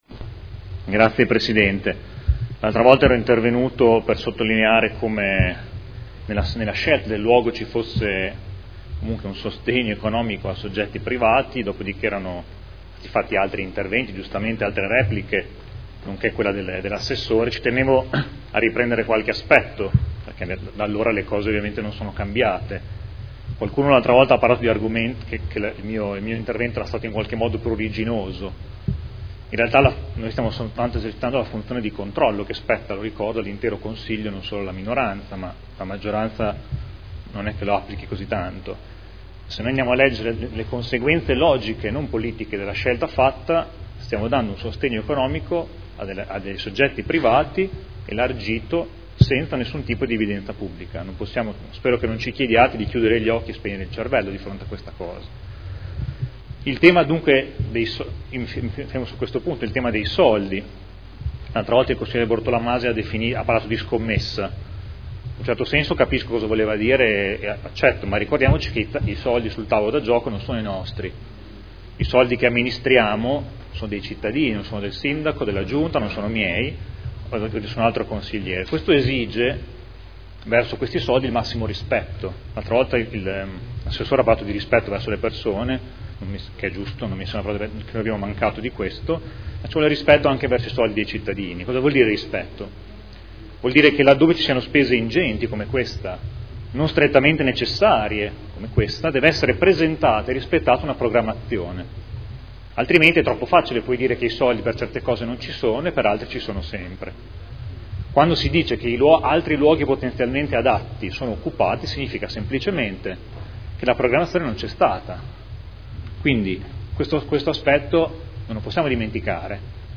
Seduta del 09/07/2015 Dibattito. Interrogazioni 81876, 83091, 85381 presentate da Scardozzi e Galli su Manifattura e mostre.